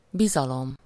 Ääntäminen
Synonyymit foi sûreté fiance abandon oreille Ääntäminen France: IPA: [kɔ̃.fjɑ̃s] Haettu sana löytyi näillä lähdekielillä: ranska Käännös Ääninäyte 1. bizalom Suku: f .